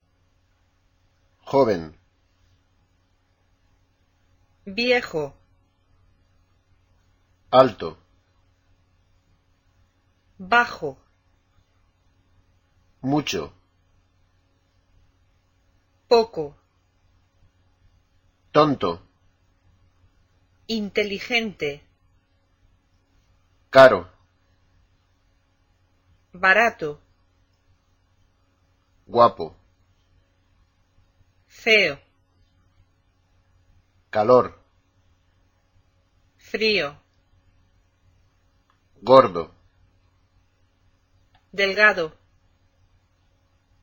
Escucha ahora las palabras anteriores y observa dónde recae el acento en la pronunciación.  Intenta repetir las mismas poniendo el énfasis de la pronunciación en la sílaba tónica.